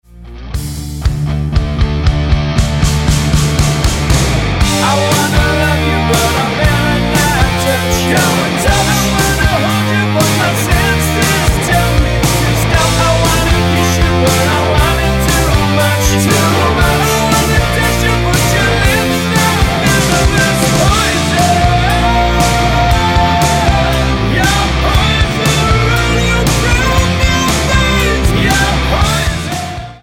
Tonart:D mit Chor